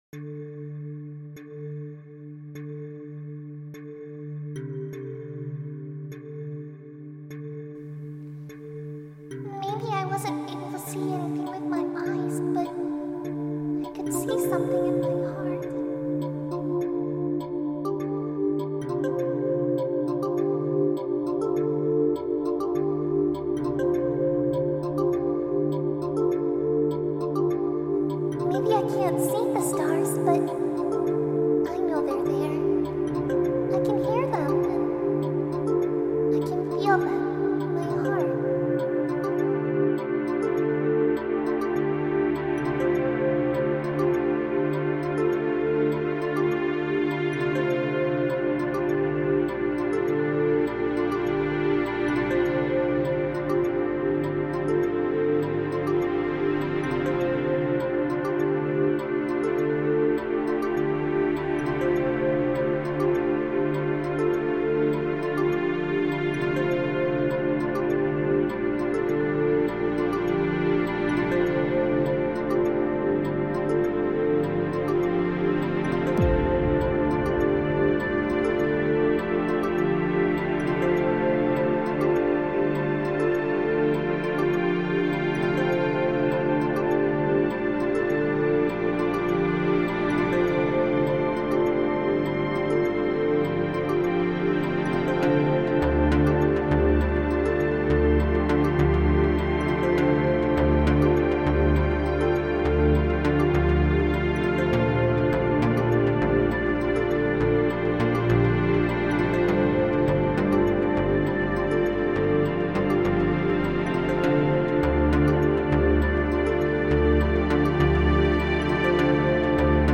Hear the electronic story of her life.
ambient journey